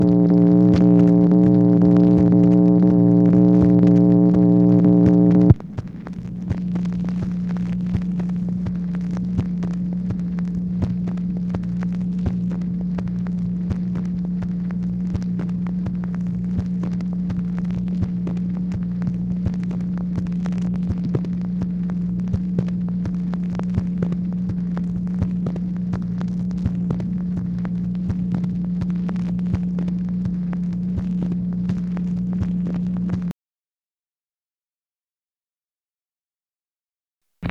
MACHINE NOISE, June 26, 1964